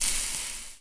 sizzle.ogg